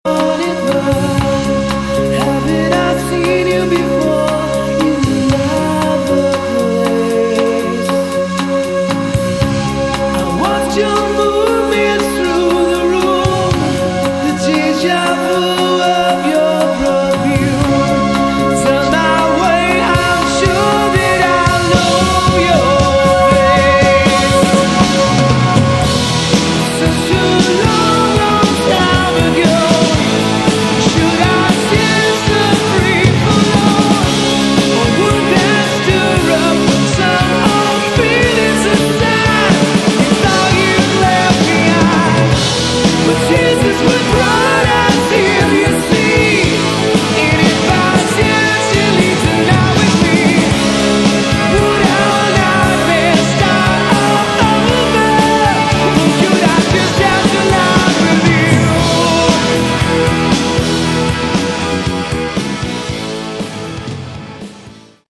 Category: AOR / Melodic Rock
lead vocals, keyboards, rhythm guitar
bass guitar, backing vocals
drums, percussion
lead guitar, spanish guitar, backing vocals